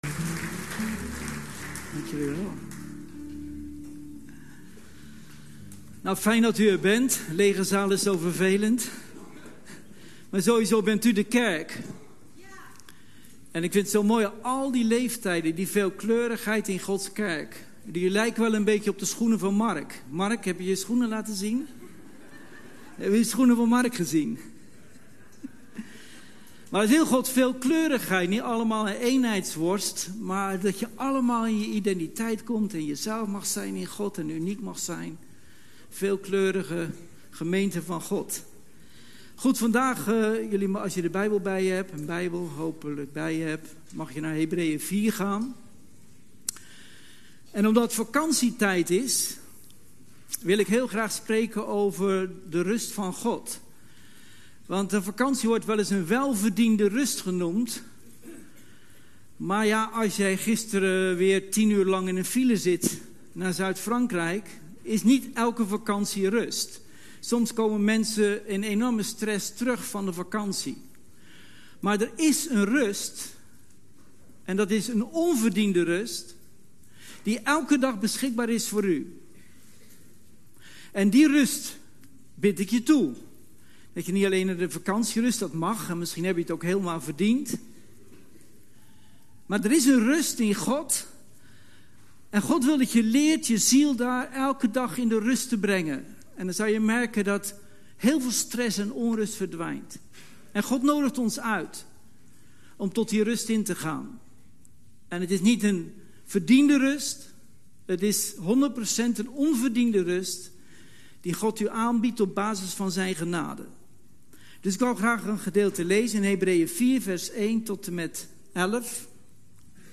Serie preken 07